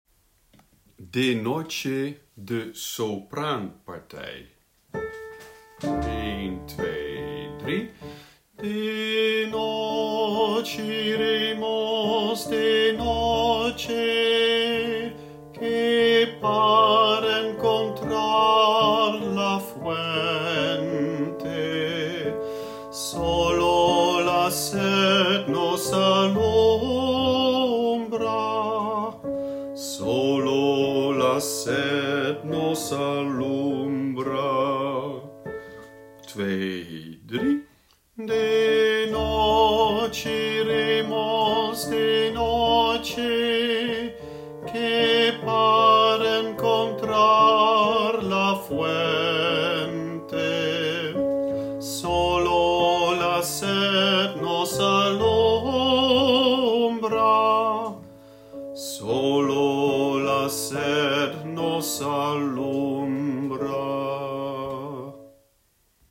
sopaan
De-noche-iremos-sopraan.mp3